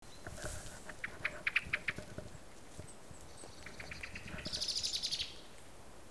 TURMERalarm1.mp3